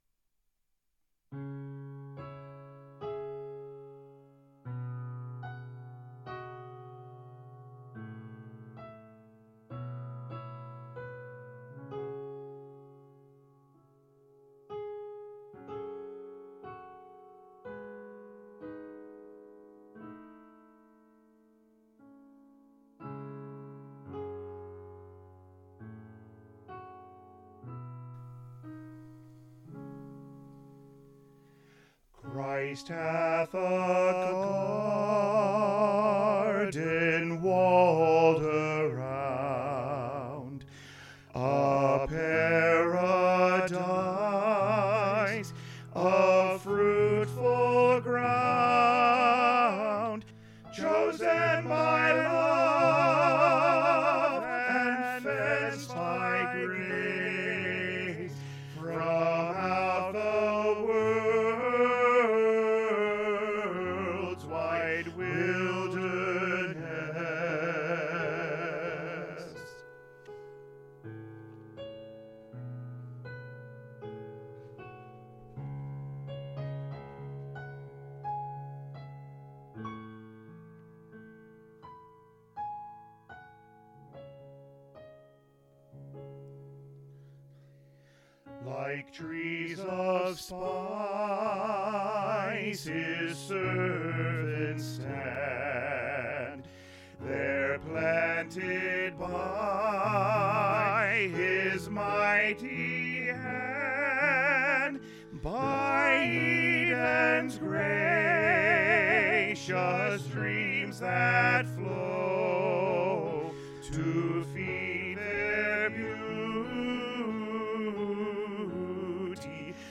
Choir Music Learning Recordings
Christ Hath a Garden - Even Mix Even Mix of all 4 Parts